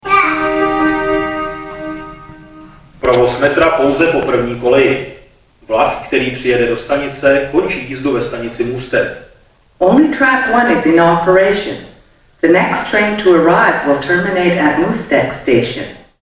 - Staniční hlášení "Vlak jede směr Můstek" si